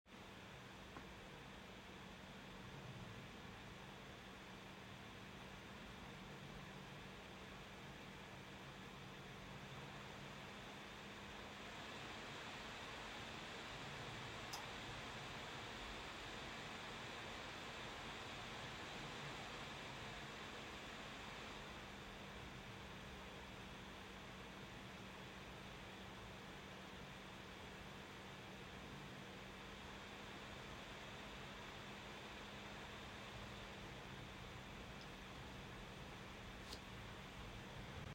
Ähnlich ist, dass alle drei Profile unter Last in Cinebench 2026 (Multi-Core) zwischen 33 und 40 Dezibel schwanken (gemessen 40 cm vor dem Display).
Geekom GeekBook X14 Pro: Kühlsystem im Modus Gleichgewicht (CB 2026 MC)